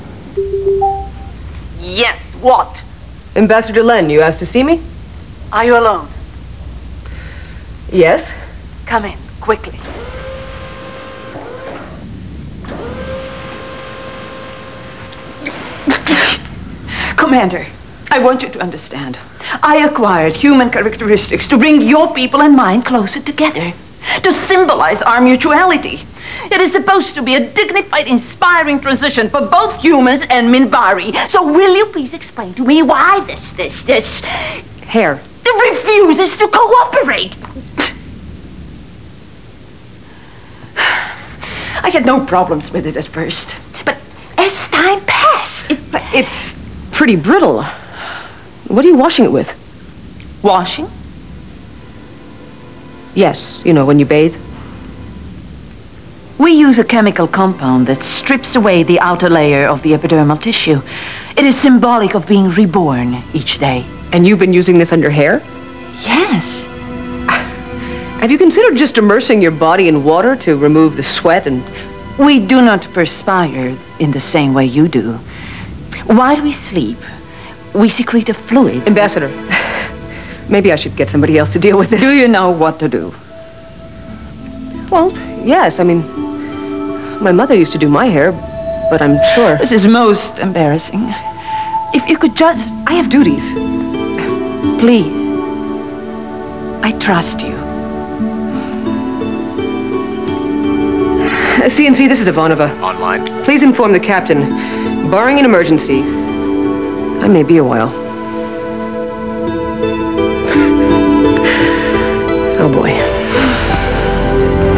1.26M The exchange between Delenn and Ivanova regarding Delenn's hair.